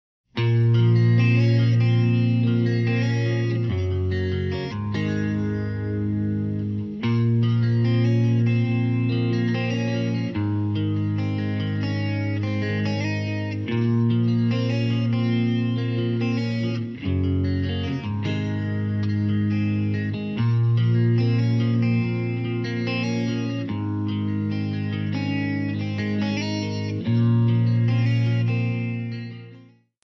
MPEG 1 Layer 3 (Stereo)
Backing track Karaoke
Rock, 2000s